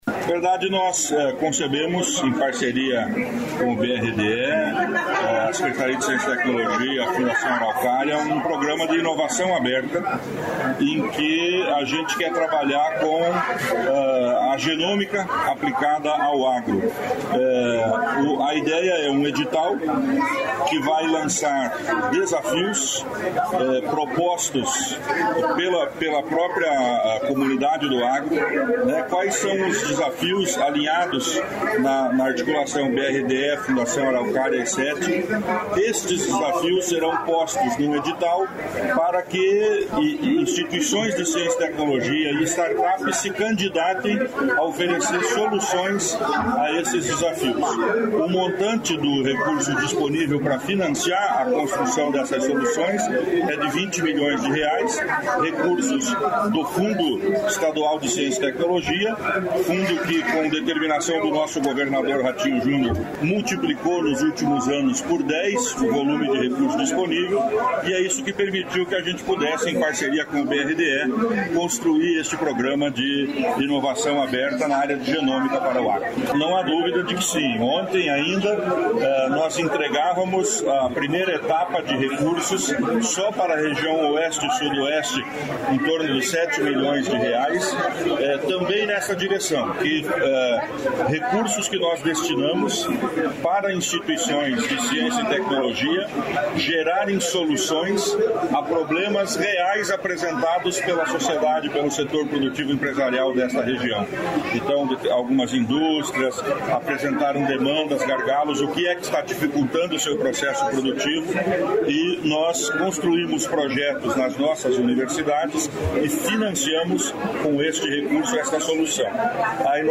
Sonora do secretário estadual da Ciência, Tecnologia e Ensino Superior, Aldo Nelson Bona, sobre inovação genômica aplicada ao agronegócio